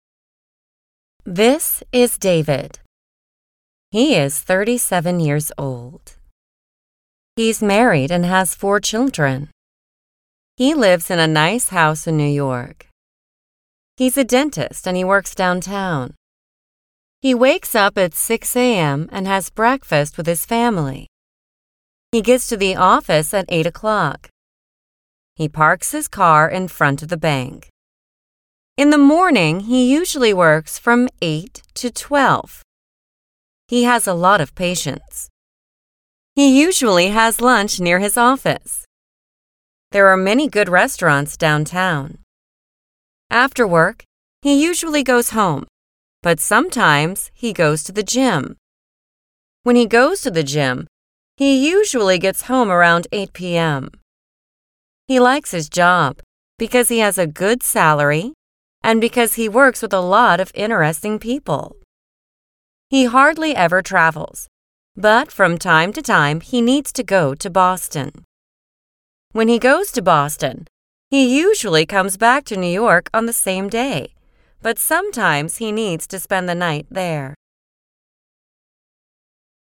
Shadowing